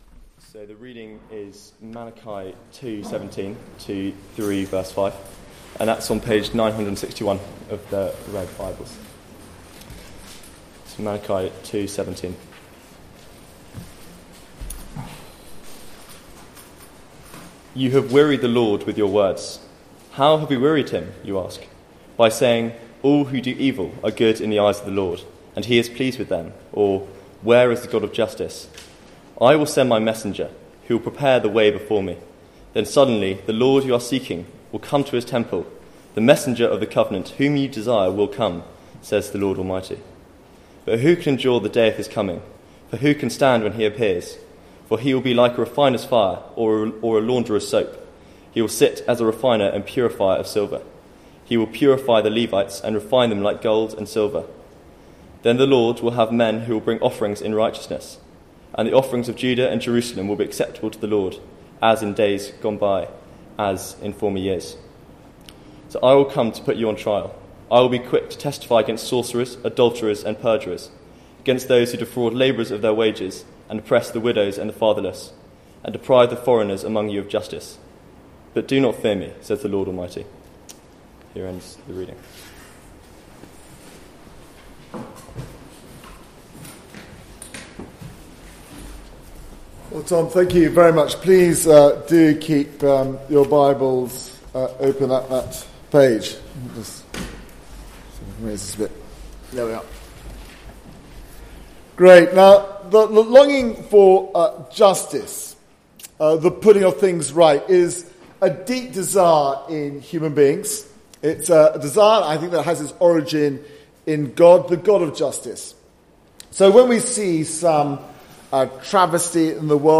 3:6 Service Type: Weekly Service at 4pm Bible Text